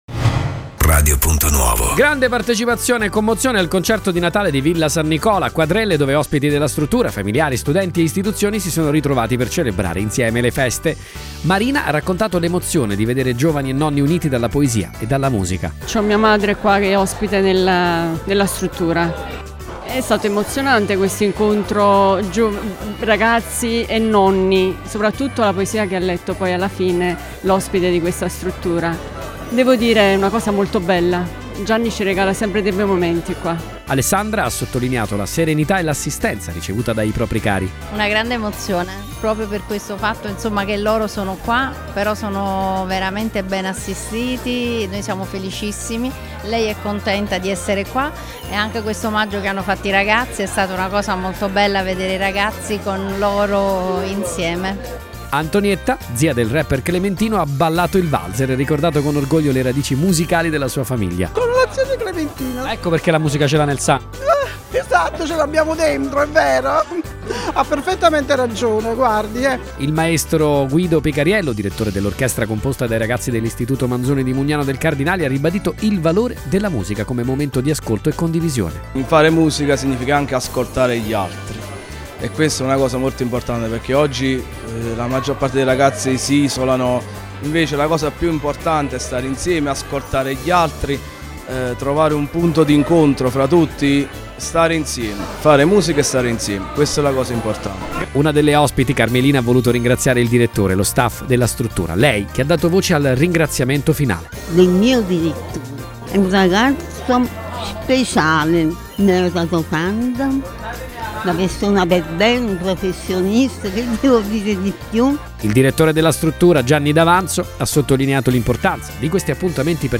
Grande partecipazione e commozione al Concerto di Natale di Villa San Nicola a Quadrelle, dove ospiti della struttura, familiari, studenti e istituzioni si sono ritrovati per celebrare insieme le feste.